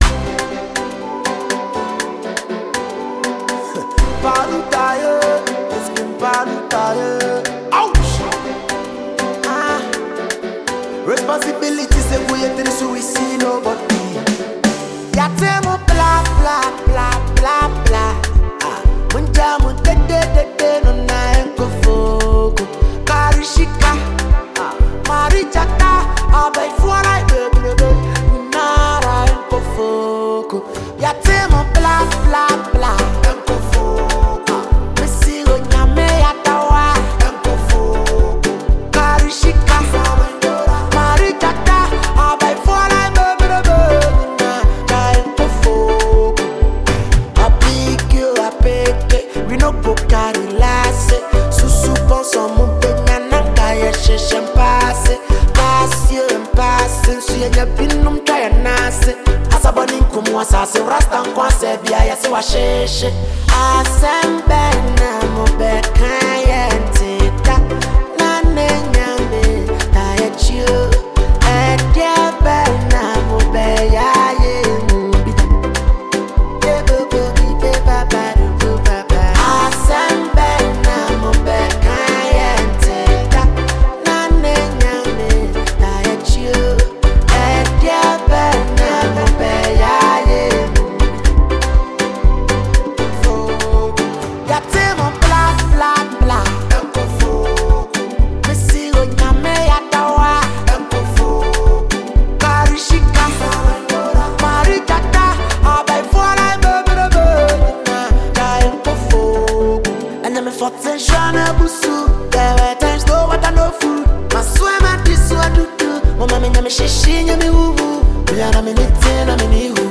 solid kicks and clean melody lines
This is real Ghana rap energy, raw and confident.